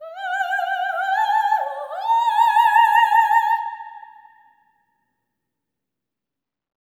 OPERATIC13-R.wav